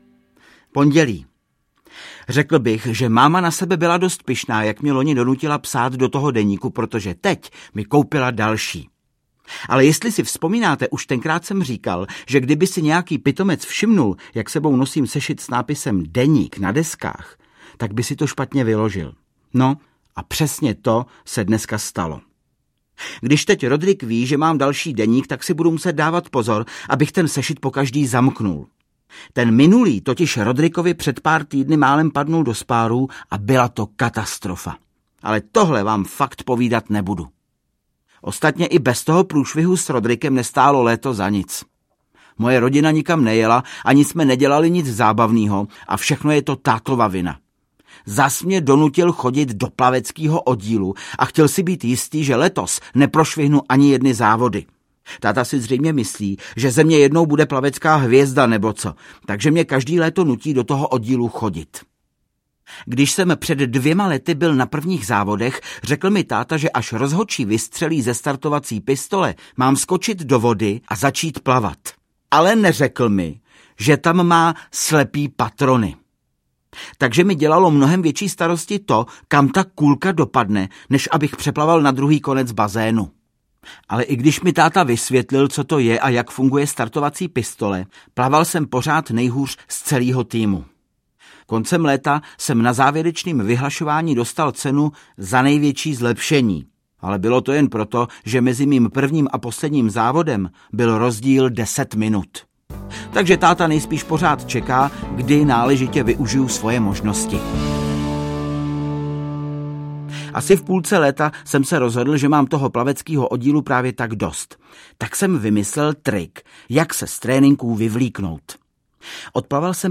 Ukázka z knihy
• InterpretVáclav Kopta
denik-maleho-poseroutky-2-rodrick-je-king-audiokniha